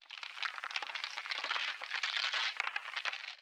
042 freezing.wav